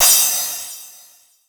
Crashes & Cymbals
MUB1 Crash 013.wav